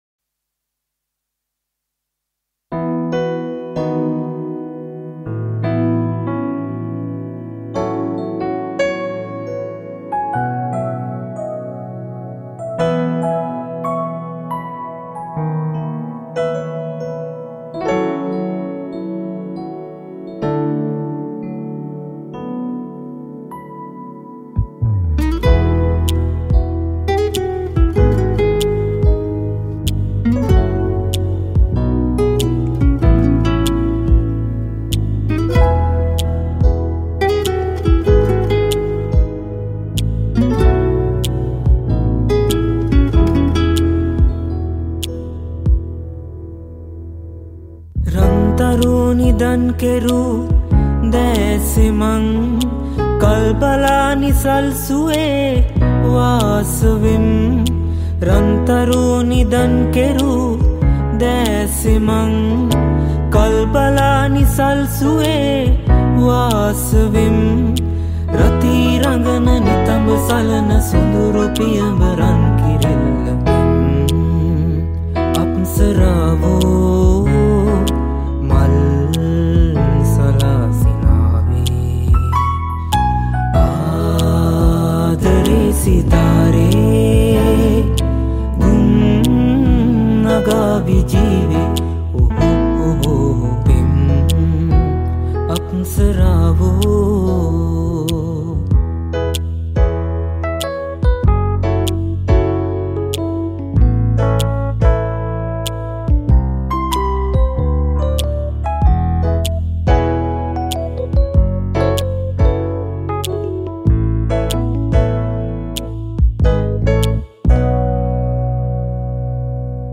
Sri Lankan remix